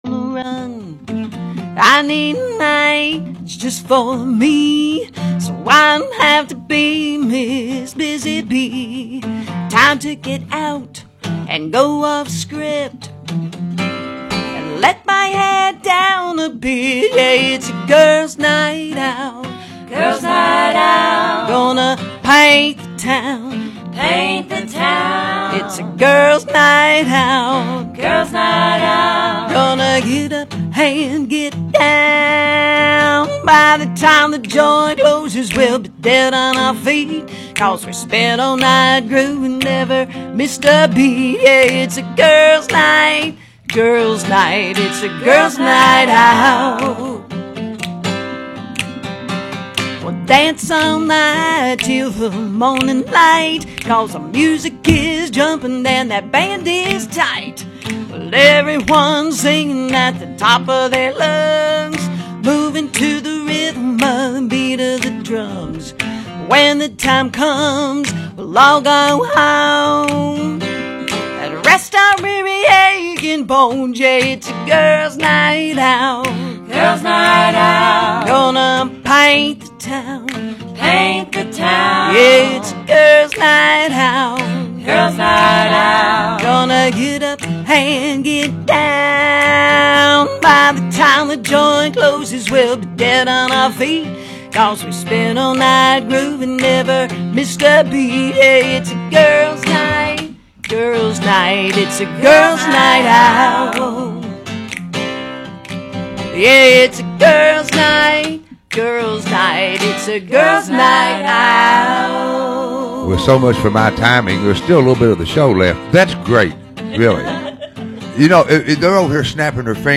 Tune in for spine-chilling tales, haunting music, and sinister trivia that will keep you on the edge of your seat.